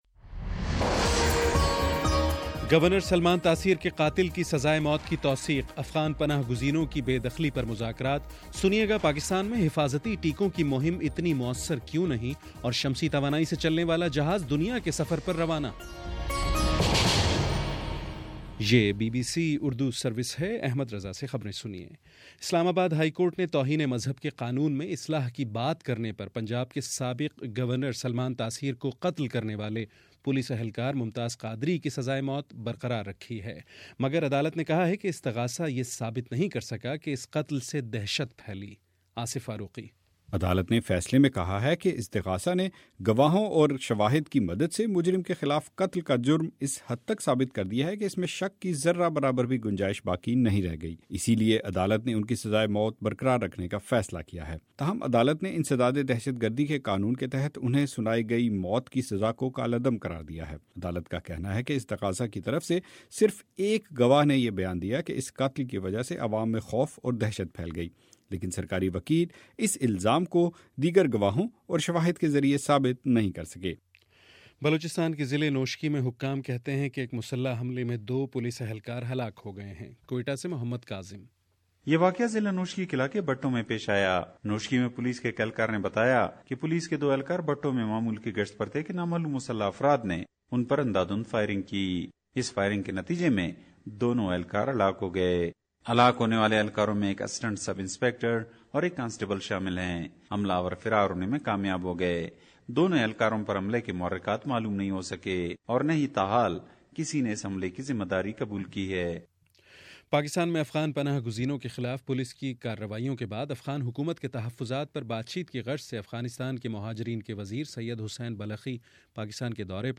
مارچ 09: شام سات بجے کا نیوز بُلیٹن